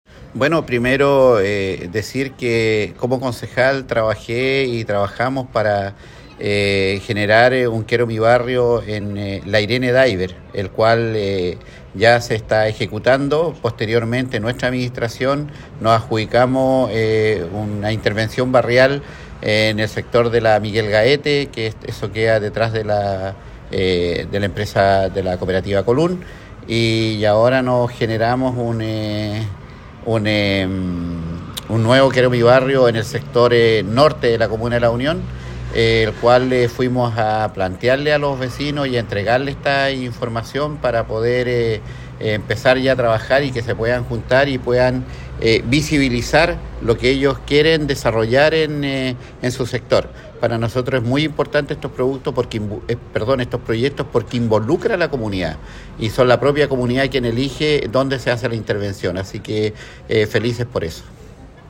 alcalde-reinoso-por-Quiero-Mi-barrio.mp3